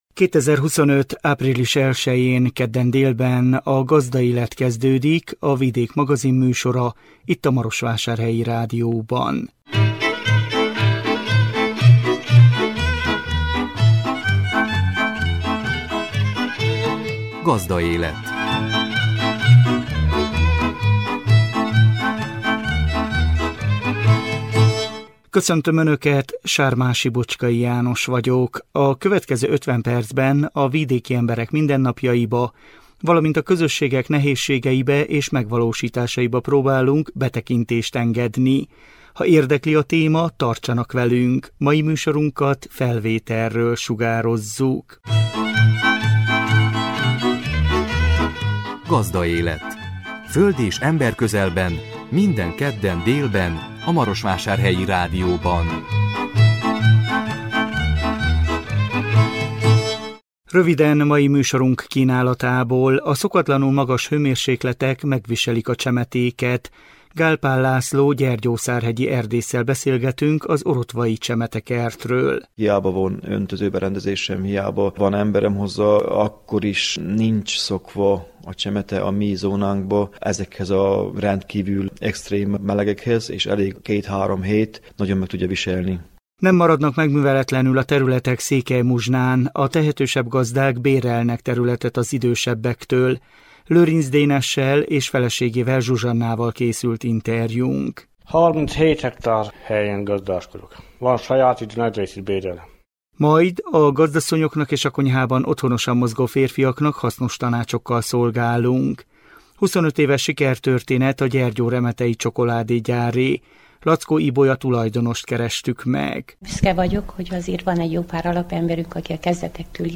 interjúnk